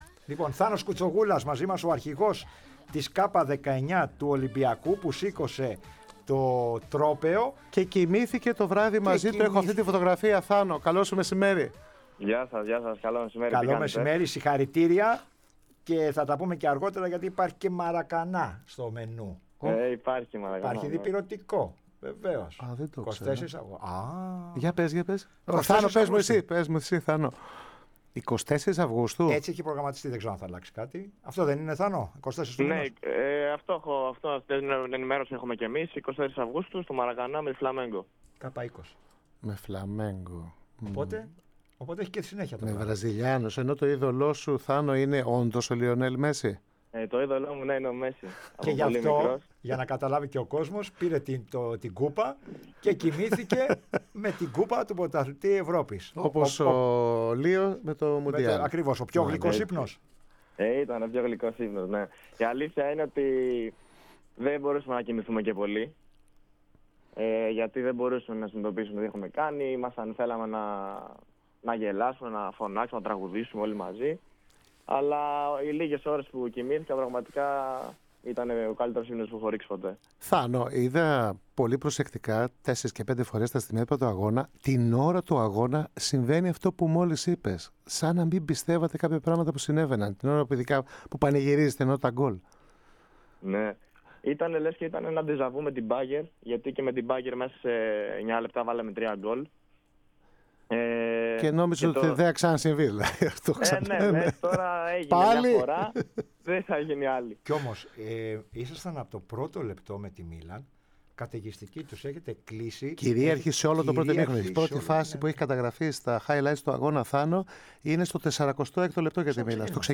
Ακούστε εδώ όλη τη συνέντευξη: https